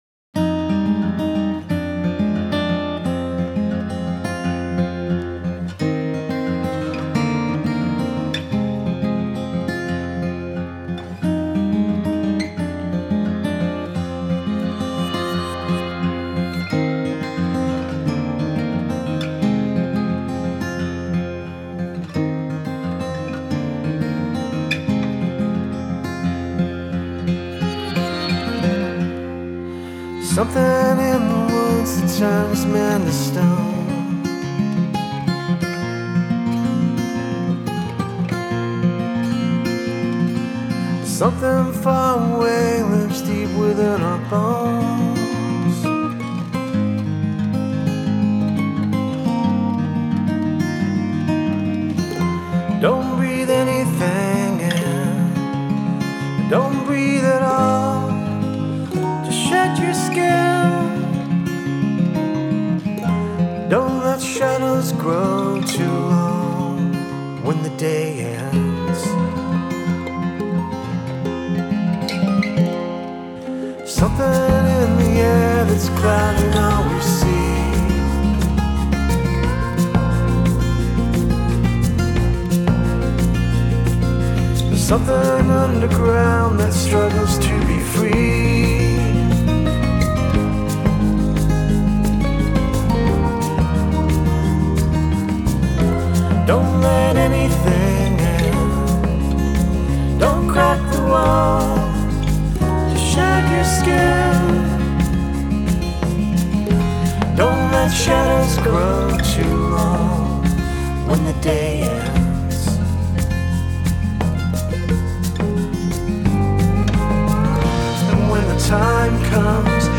"Something in the Woods" - Indie folk